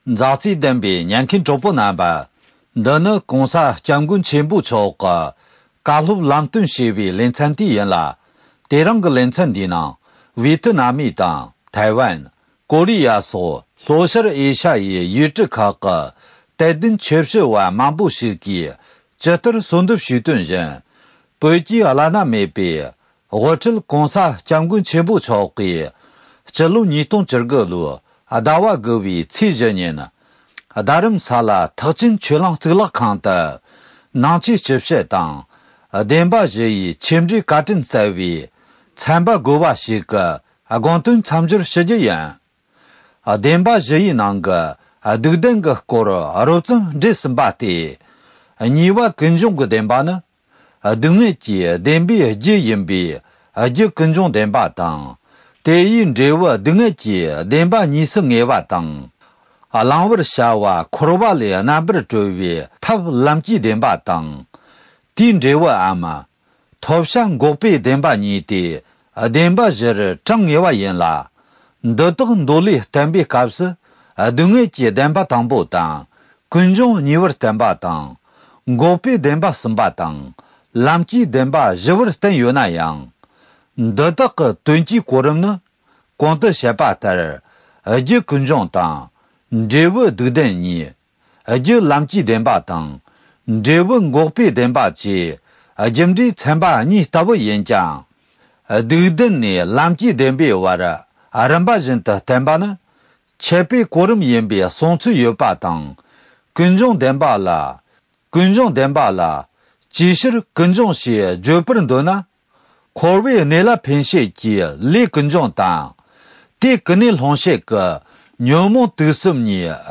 ཕྱི་ལོ་༢༠༡༩ ཟླ་ ༩ པའི་ཚེས་༤ཉིན་བཞུགས་སྒར་ཐེག་ཆེན་ཆོས་གླིང་གཙུག་ལག་ཁང་དུ་ཆོས་འབྲེལ་བཀའ་ཆོས་བསྩལ་གནང་མཛད་ཡོད་པ།